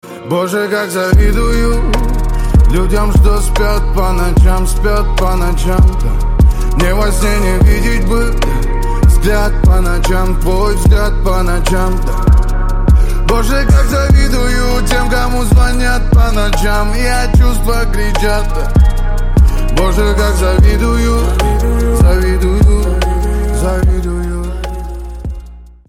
лирика
грустные
красивый мужской голос